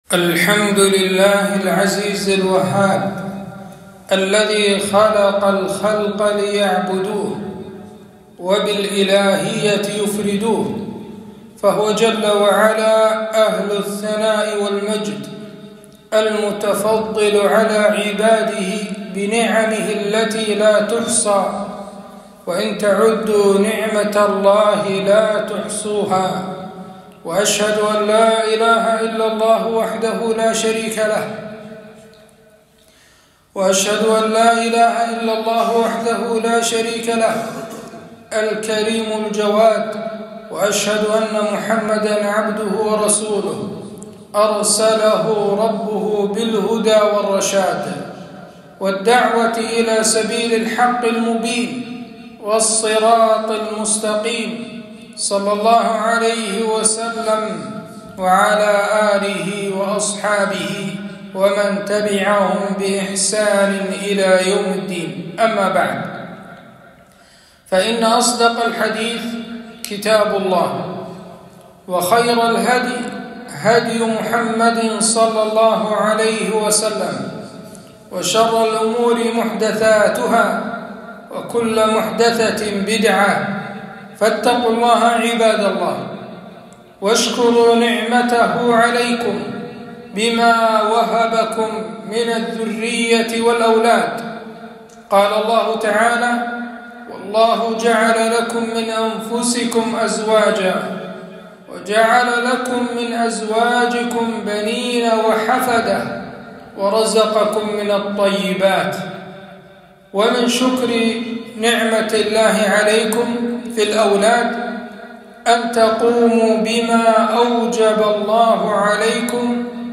خطبة - تربية الأولاد في الإسلام